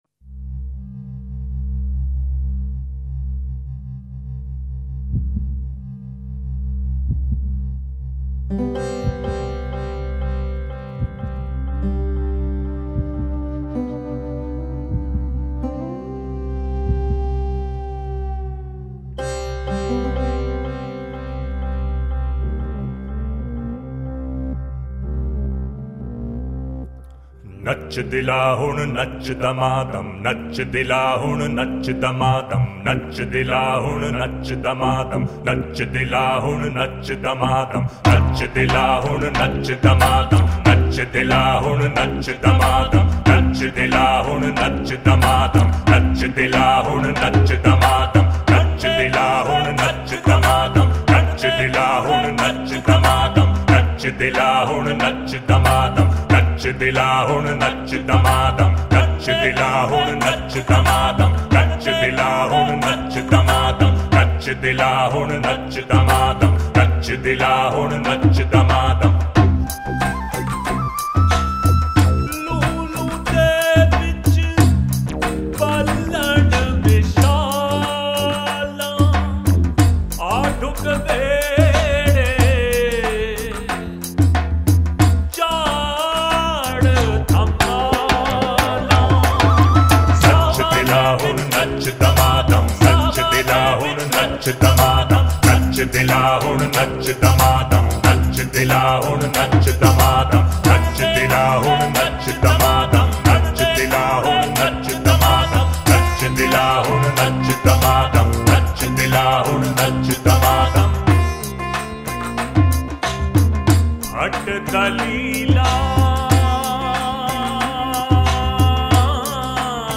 upbeat Punjabi song